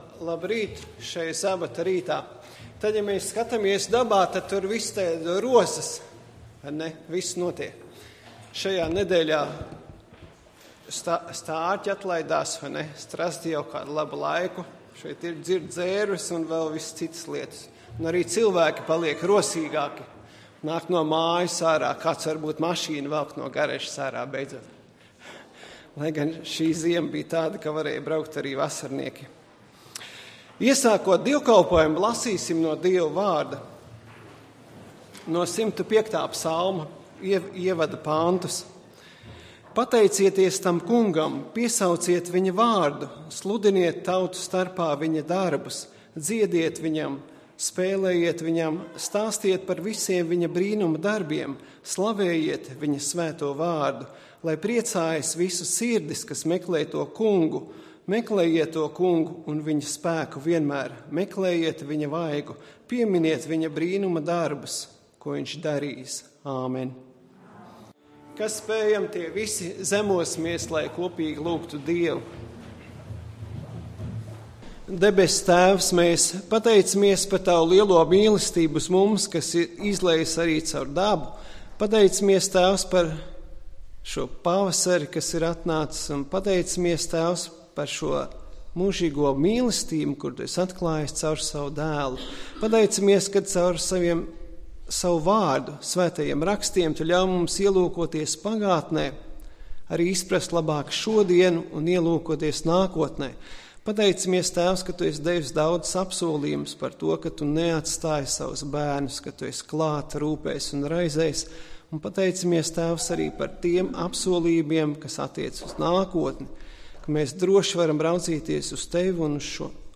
Dievkalpojums 05.04.2014: Klausīties
Svētrunas